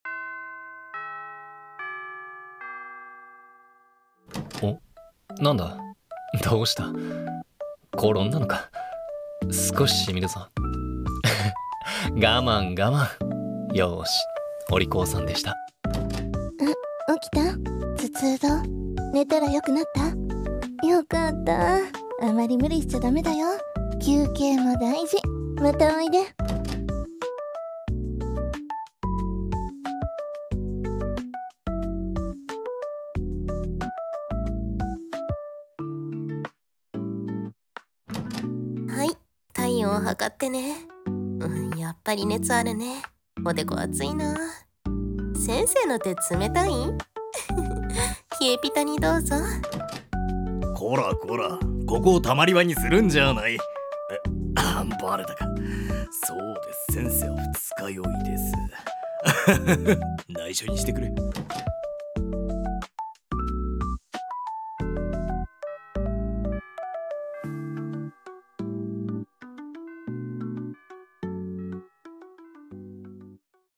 【保健室の先生 動画付き6人声劇】